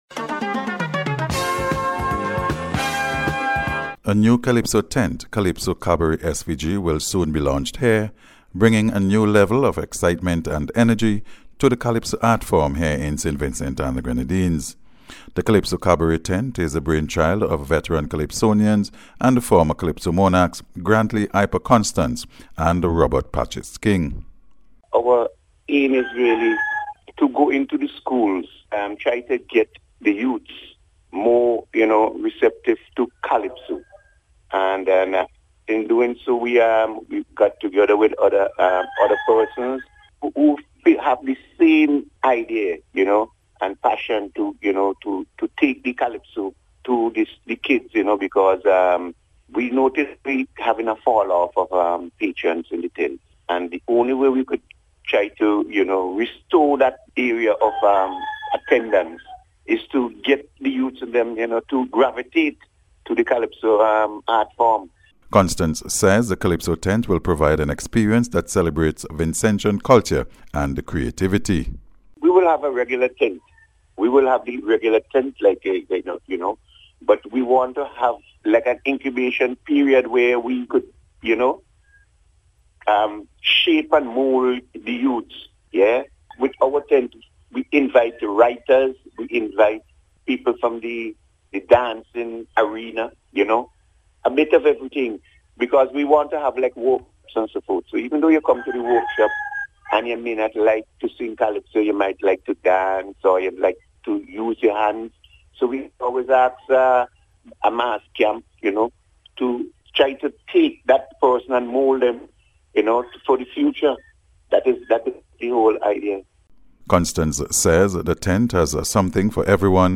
CALYPSO-CABARET-REPORT.mp3